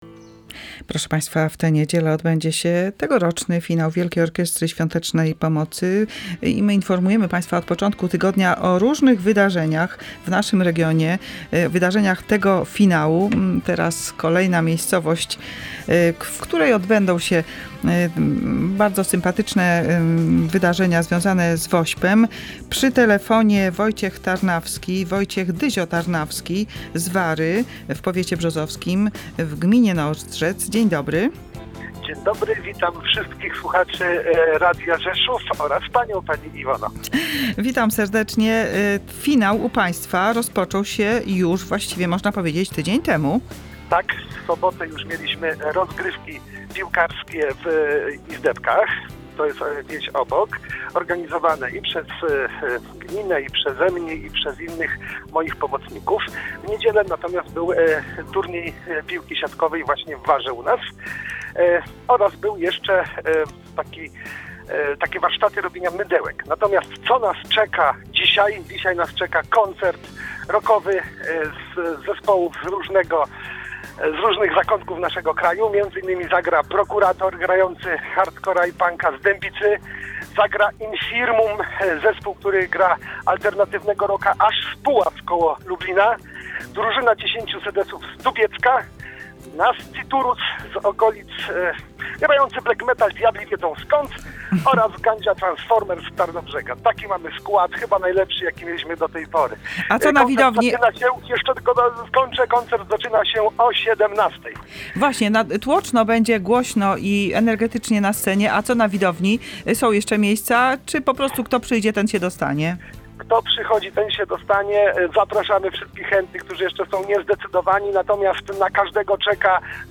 W audycji „Tu i Teraz” kontynuowaliśmy temat Finału Wielkiej Orkiestry Świątecznej Pomocy. W rozmowie telefonicznej przenieśliśmy się do Wary, w powiecie brzozowskim.